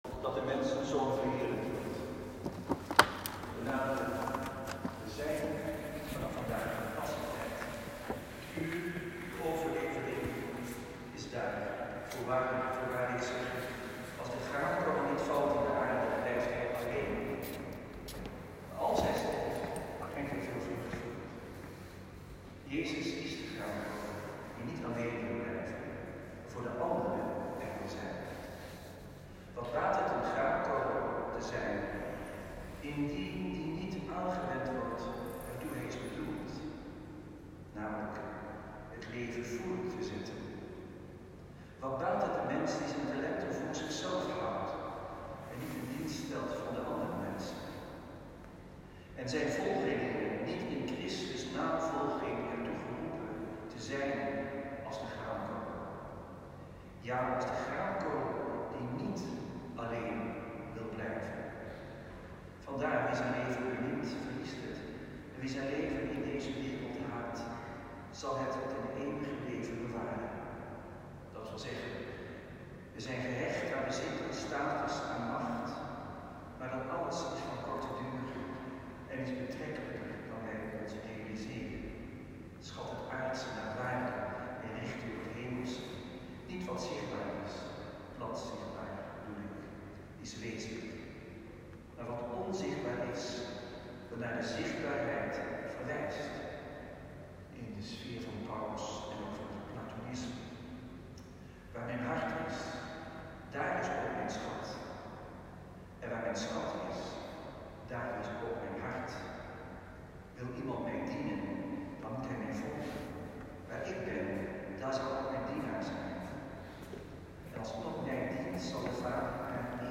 Vijfde zondag van de vasten B. Celebrant Antoine Bodar.
Preek-1.m4a